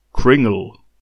Kringle (/ˈkrɪŋɡəl/,